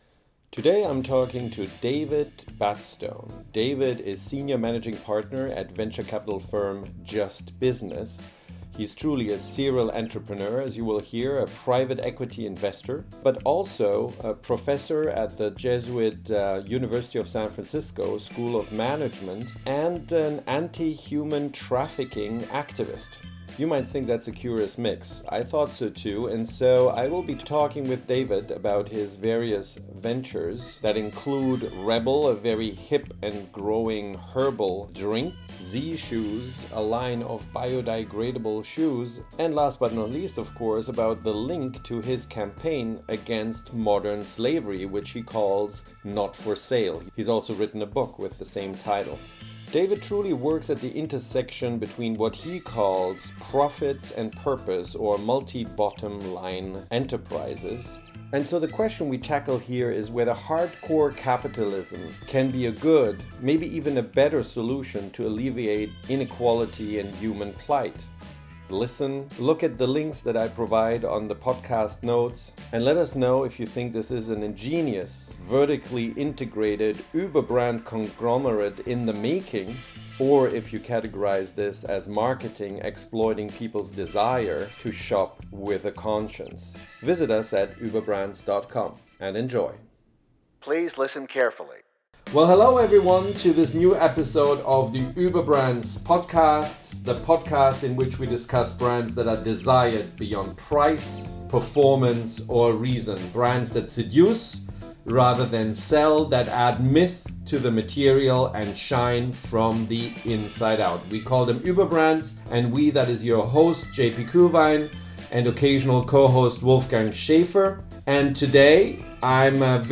Profit and/or Purpose? – Interview